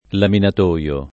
[ laminat 1L o ]